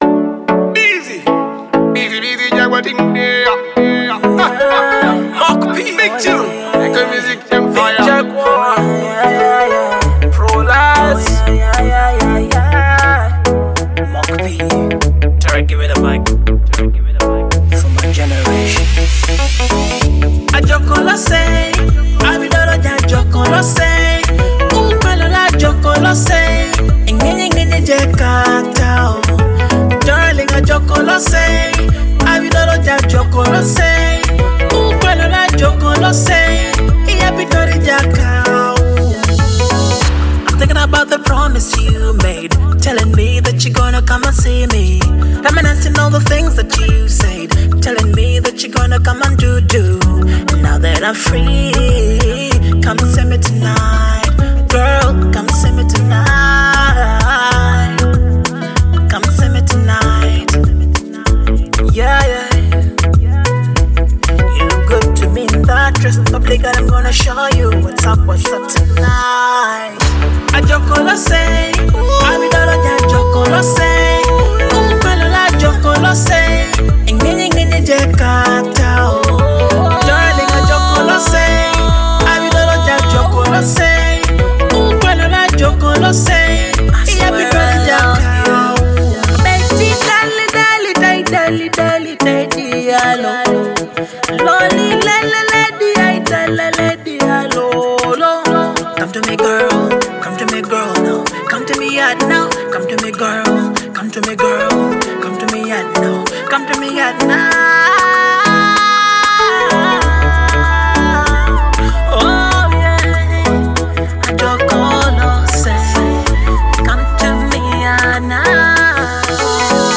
Melancholic